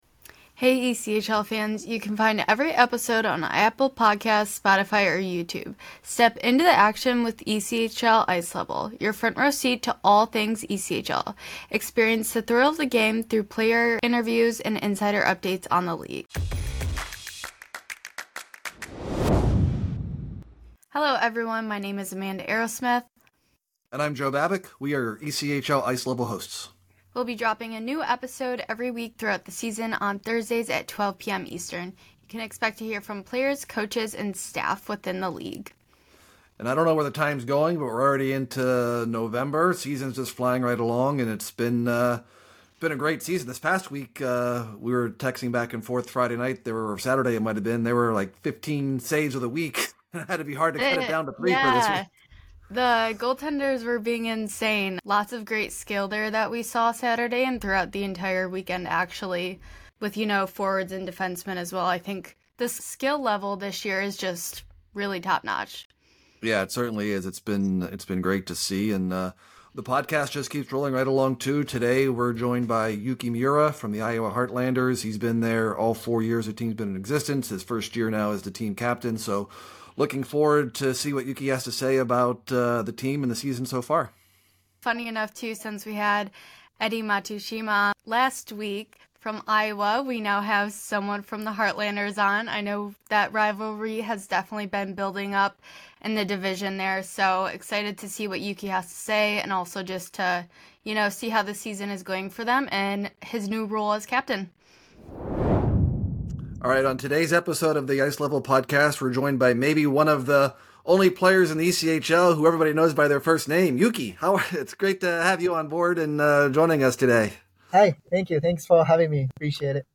Experience the thrill of the game through player interviews, and insider updates on the league.